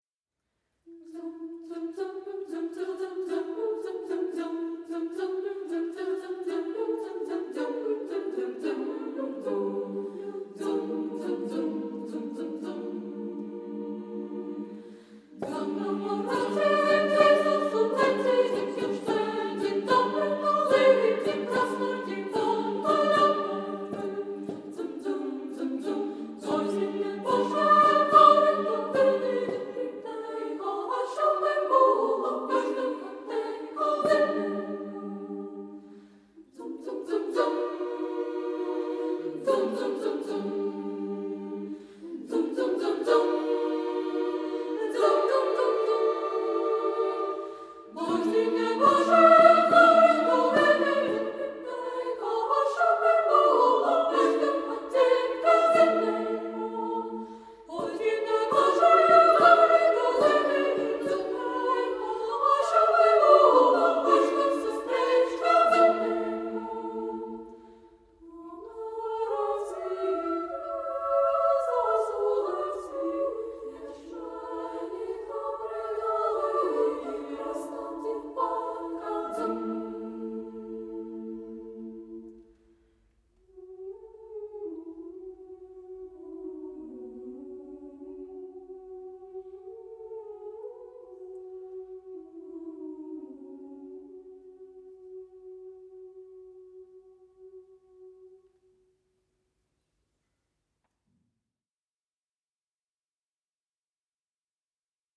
SSAA div. a cappella choir
imitating a plucked instrument